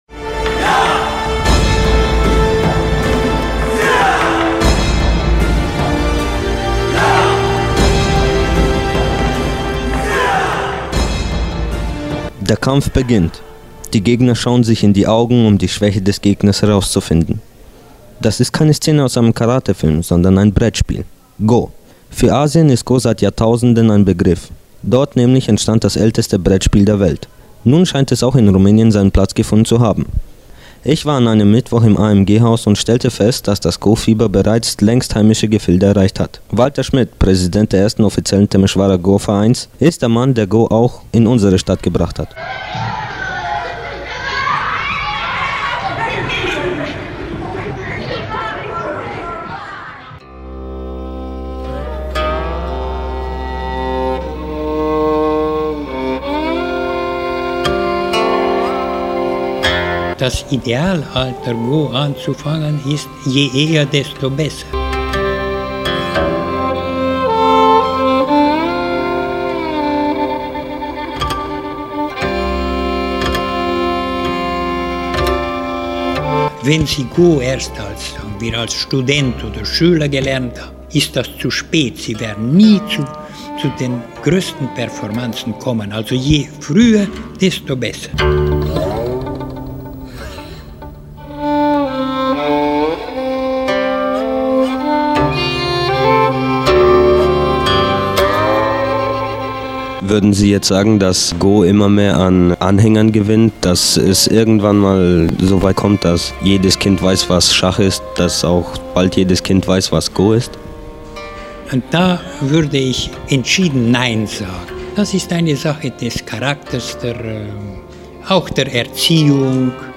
INTERVIU (5min):
interviu-lenau-2008.mp3